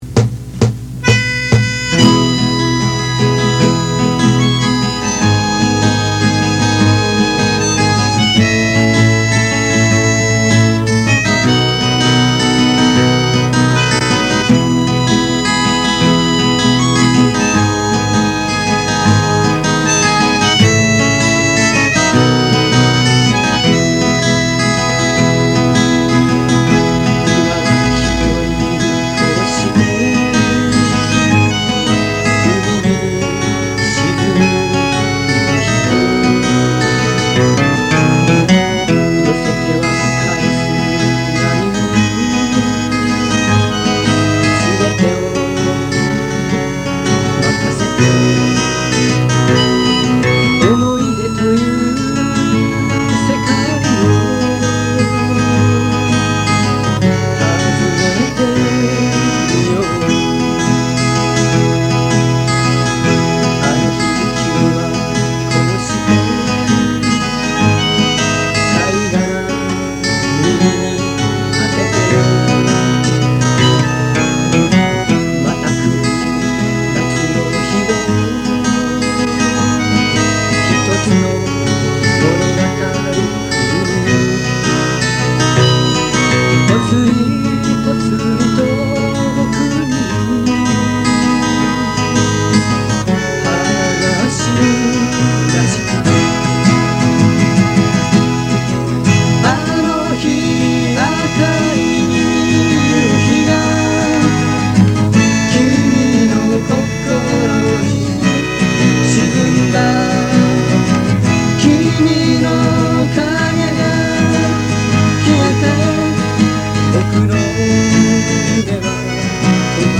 1979年(高3)の時にメンバーが集まりカセットテープレコーダーで録音したものです。